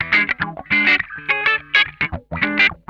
CRUNCHWAH 3.wav